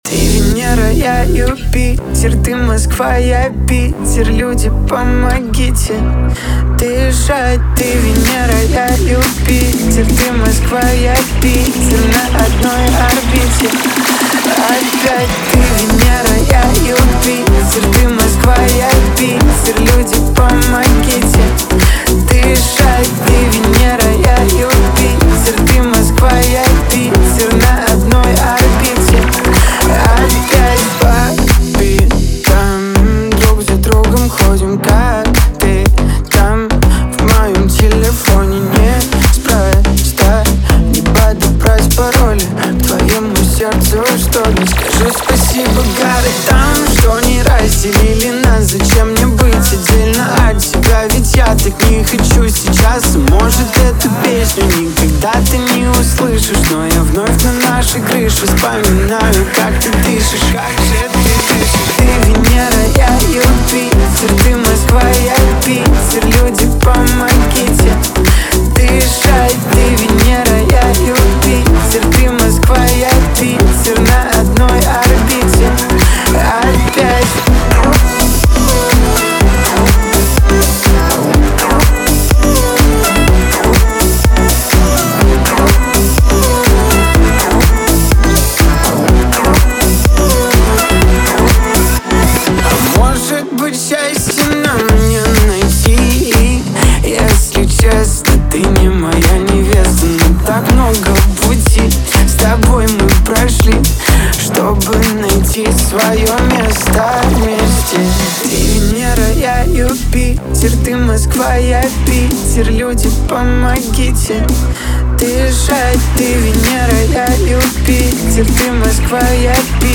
это яркая и запоминающаяся поп-композиция